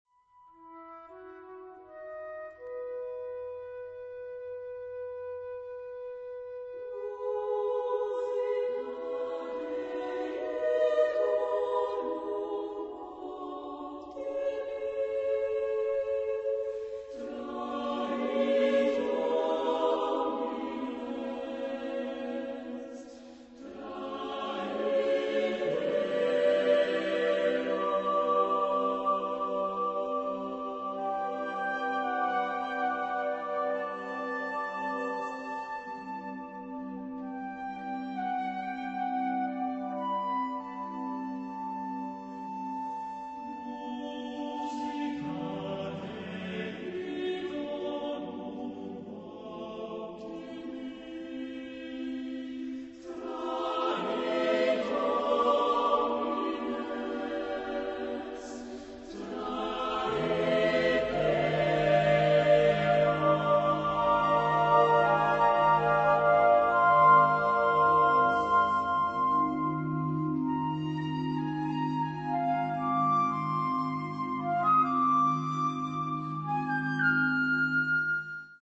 Genre-Style-Forme : Chœur ; Sacré
Caractère de la pièce : andante
Type de choeur : SATB  (4 voix mixtes )
Instruments : Flûte (1) ; Orgue (1)
Tonalité : mode de mi